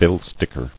[bill·stick·er]